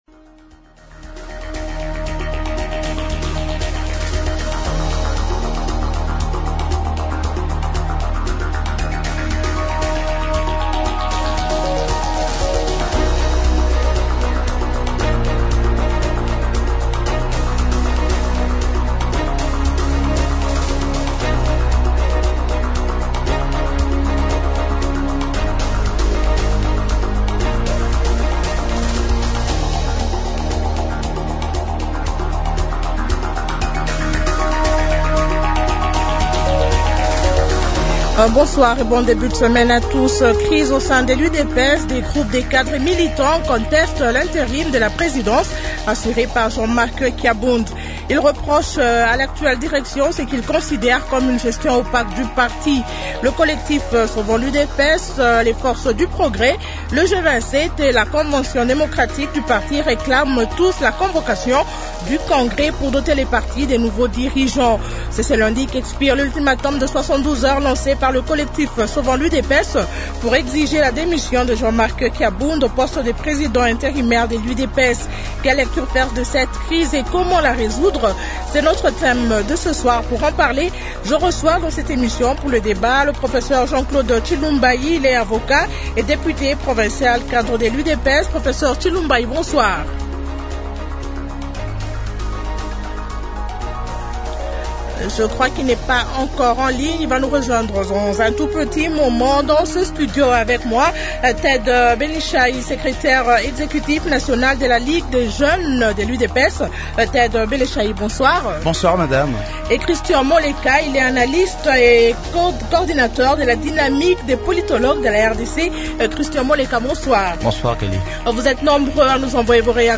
Il est Député provinciale et cadre de l’UDPS.